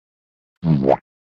bwomp
Tags: dnd kenku silly yippee